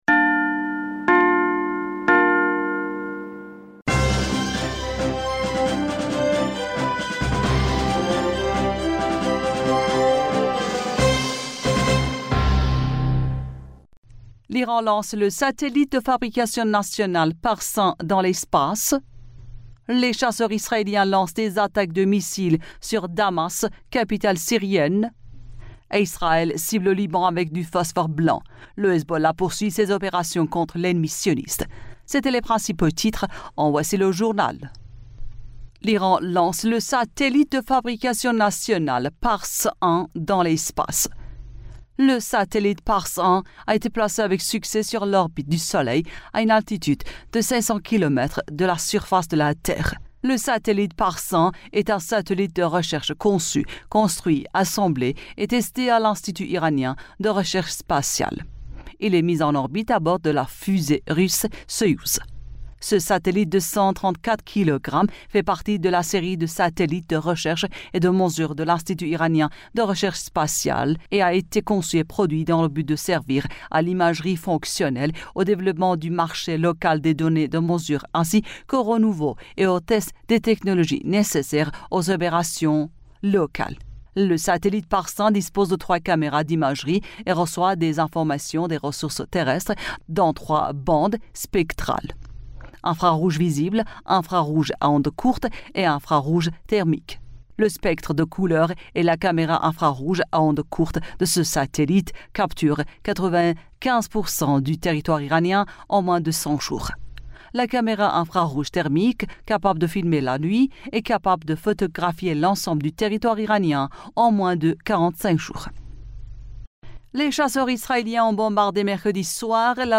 Bulletin d'information du 29 Fevrier 2024